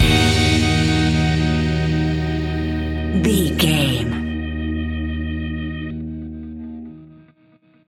Ionian/Major
E♭
pop rock
indie pop
fun
energetic
uplifting
electric guitar
Distorted Guitar
Rock Bass
Rock Drums
hammond organ